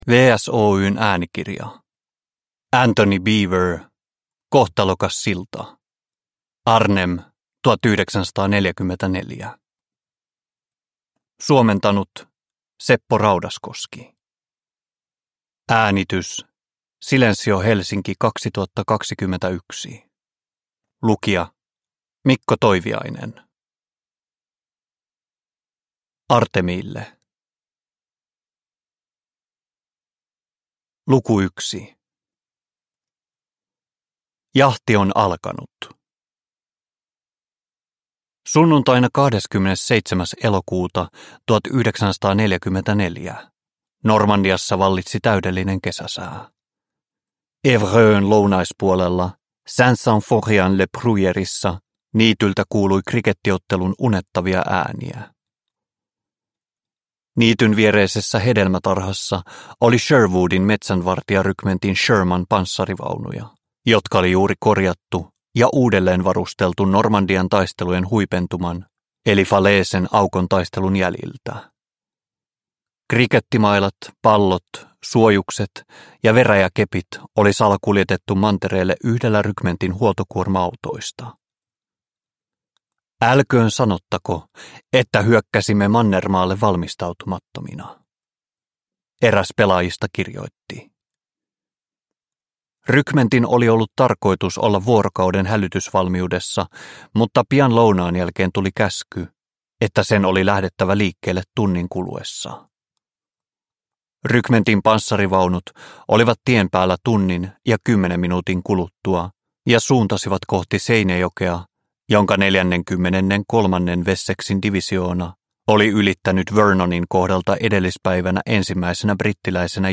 Kohtalokas silta – Ljudbok – Laddas ner